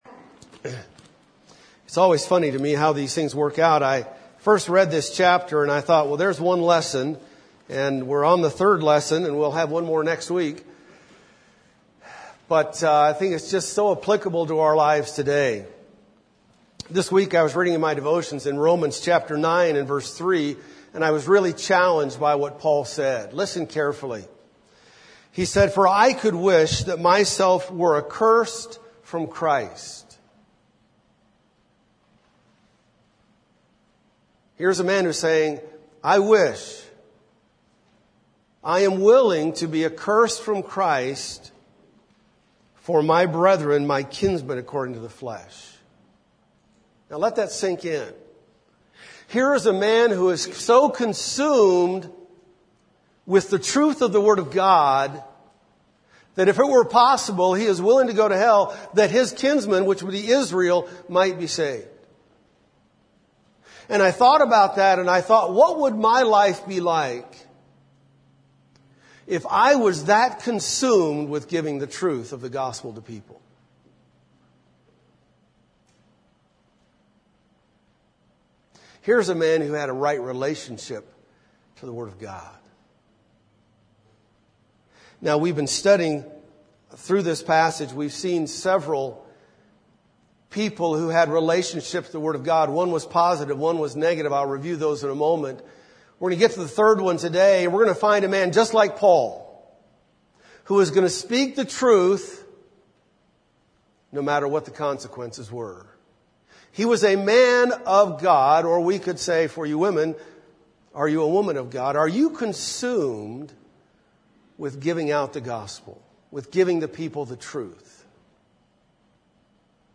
2017 Sunday School Lessons